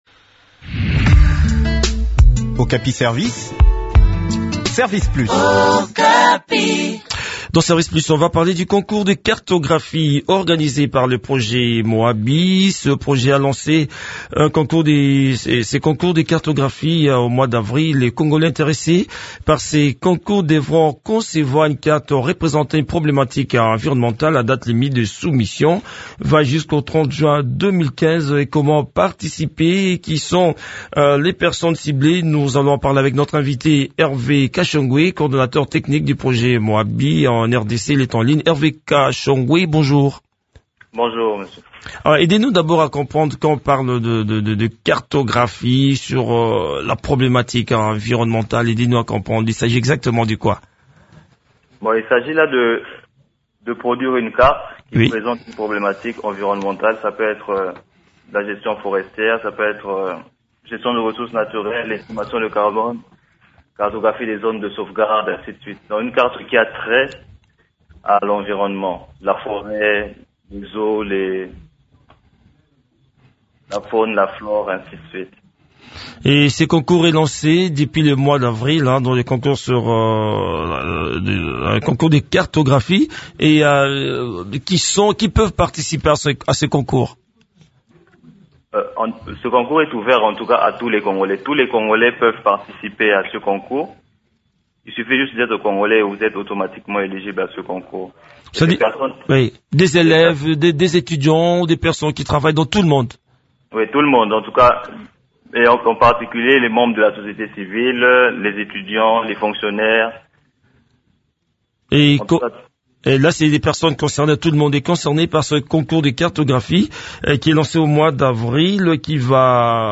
Le point sur les conditions de soumission des dessins dans cet entretien